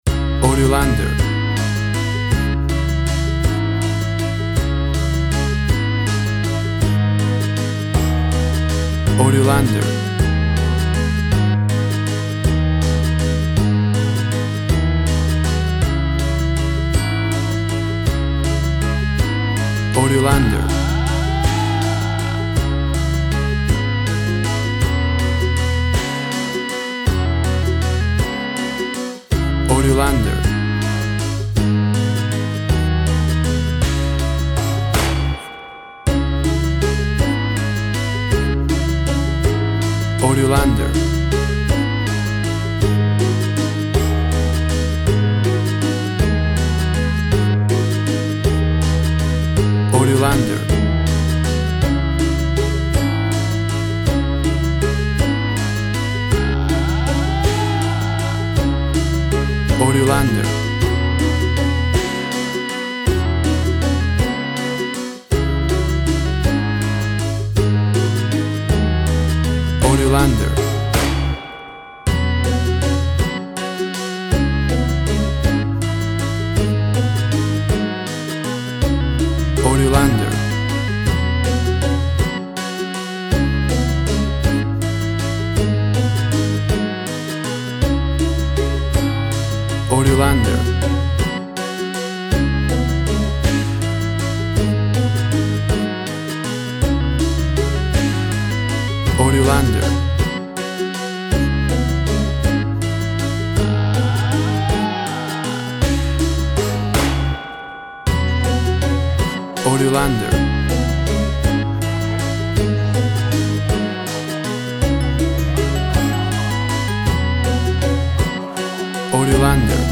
WAV Sample Rate 16-Bit Stereo, 44.1 kHz
Tempo (BPM) 76